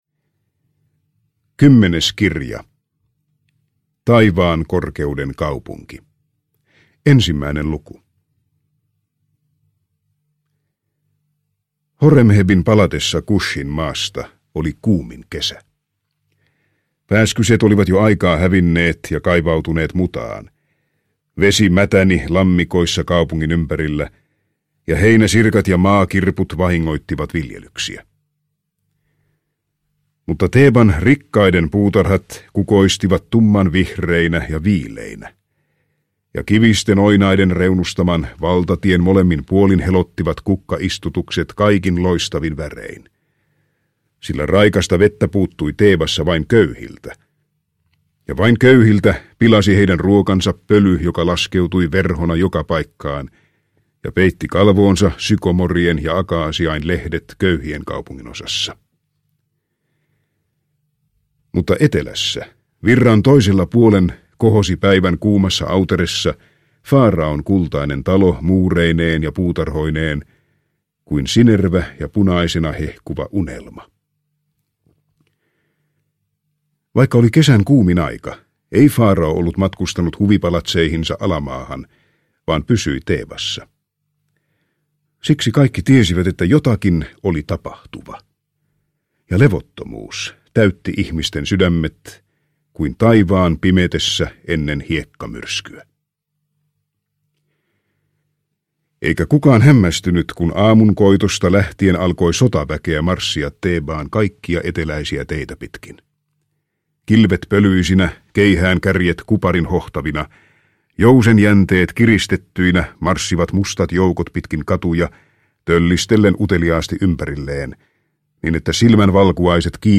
Sinuhe egyptiläinen osa 2 – Ljudbok – Laddas ner